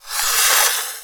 fireball_conjure_05.wav